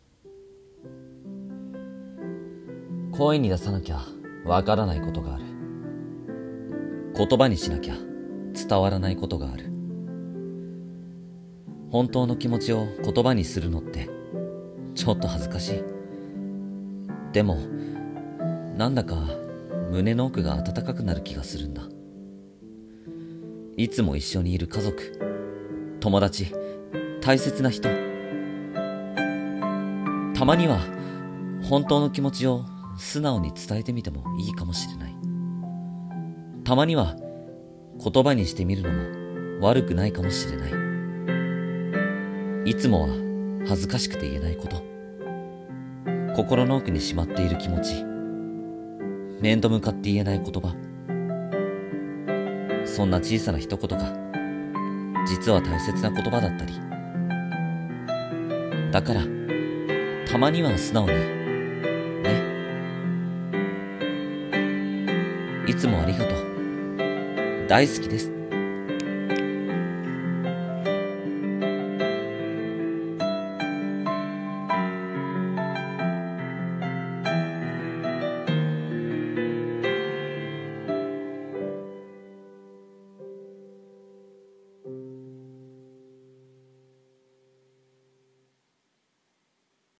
[声劇･朗読]たまには素直に[台本